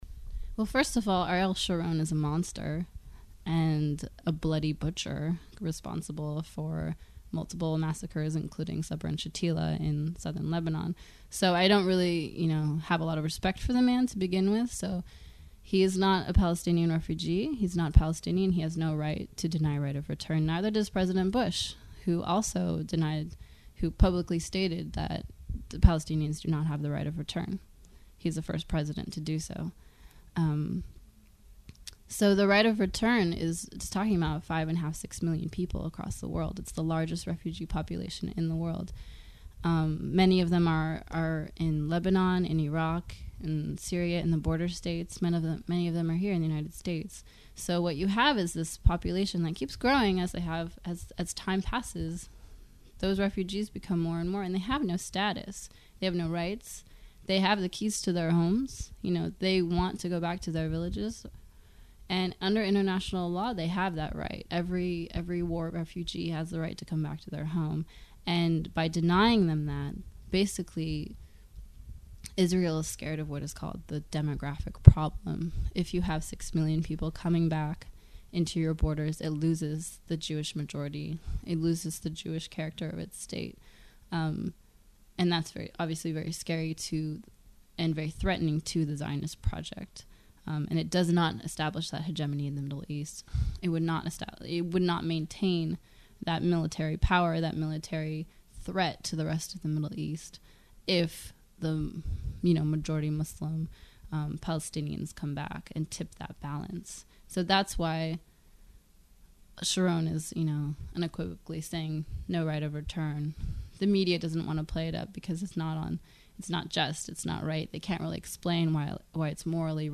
Palestine Interview